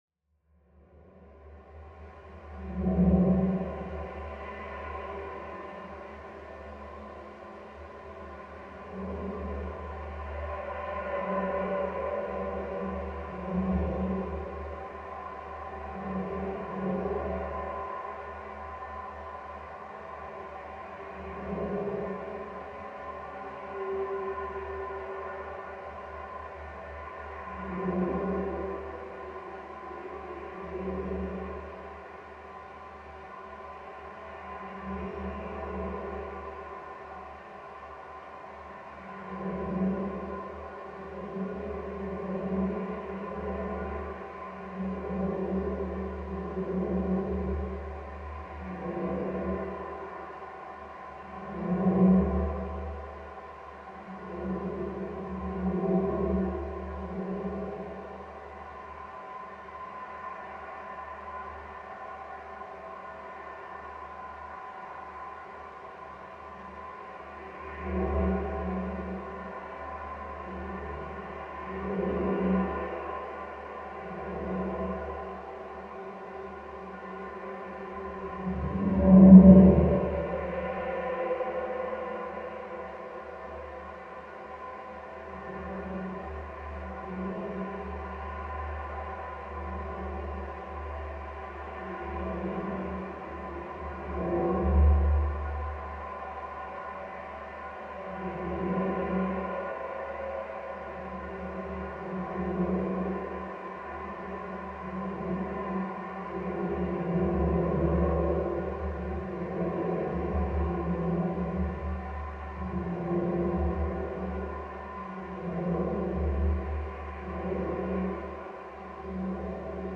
No.2 underpass of Binhai Avenue, Shenzhen